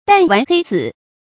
彈丸黑子 注音： ㄉㄢˋ ㄨㄢˊ ㄏㄟ ㄗㄧˇ 讀音讀法： 意思解釋： 亦作「彈丸黑志」。